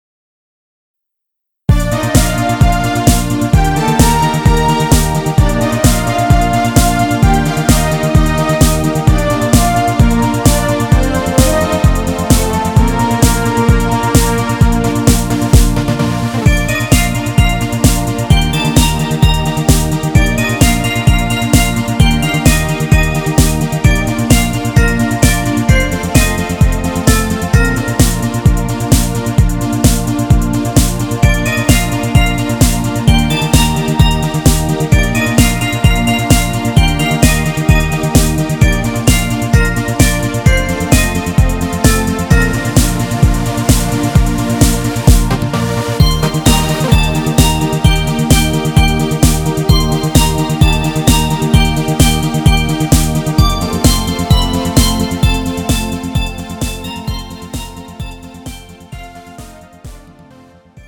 음정 Bb 키
장르 가요 구분 Pro MR